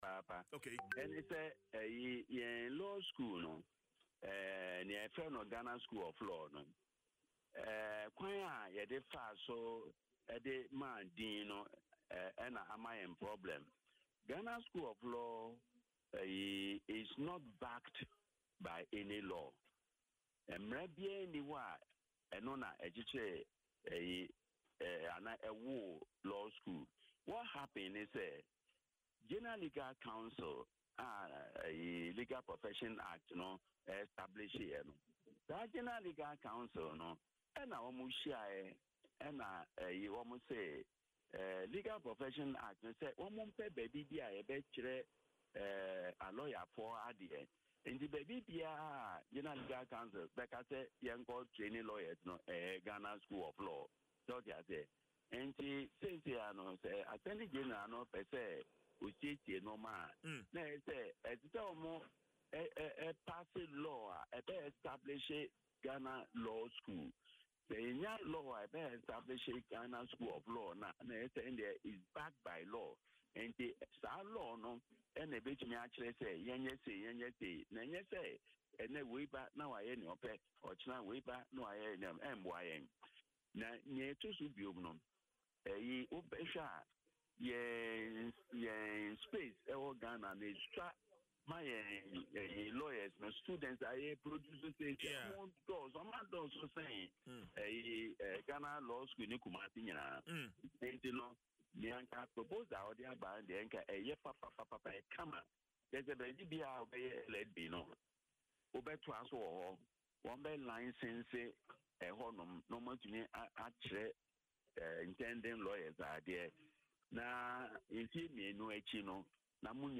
In an interview on Adom FM’s Dwaso Nsem, the seasoned legal practitioner described the move as timely and commendable, urging the Attorney General to ensure its full implementation.